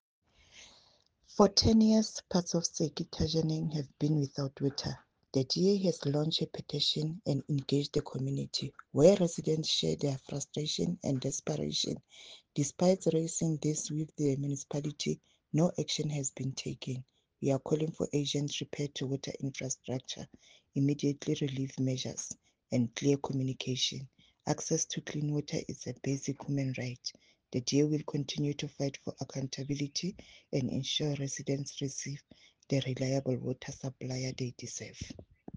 Issued by Cllr. Ana Motaung – DA Councillor Maluti-a-Phofung Municipality
Sesotho soundbites by Cllr Ana Motaung and